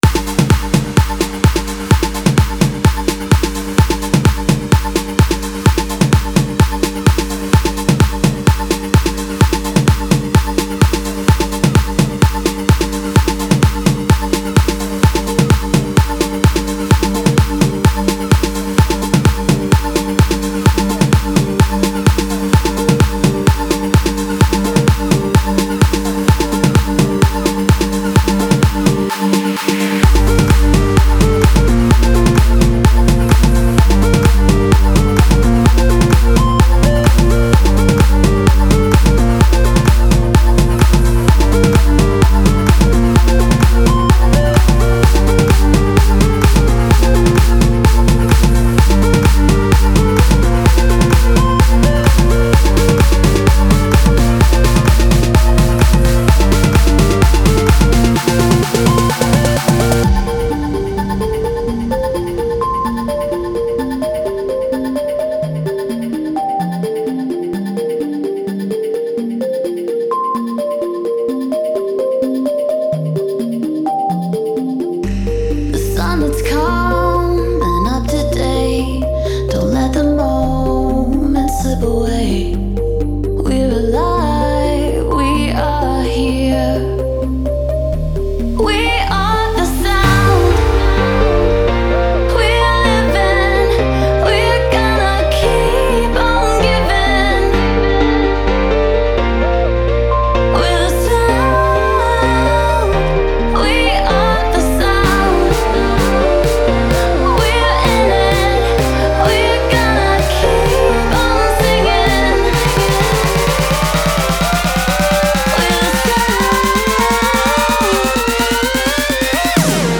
Стиль: Vocal Trance